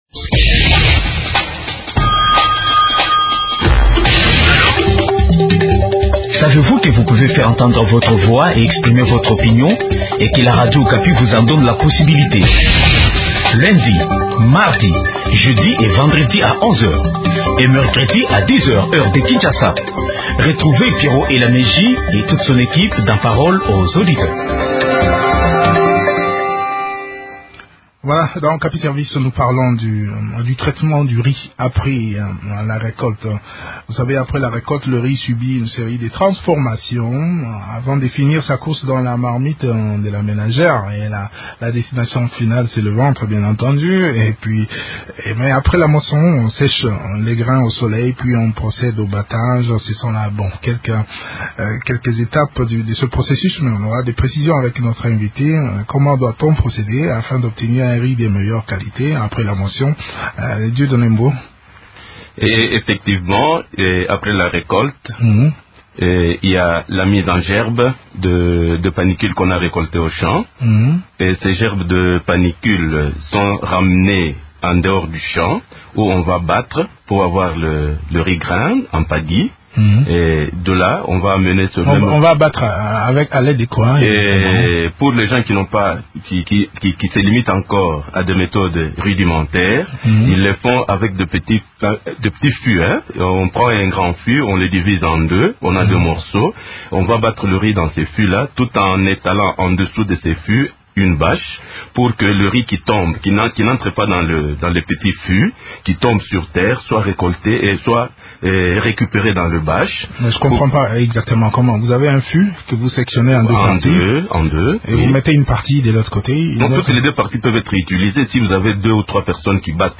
ingénieur agronome.